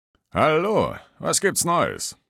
Datei:Maleadult02 mq04 greeting 00095b3d.ogg
Fallout 3: Audiodialoge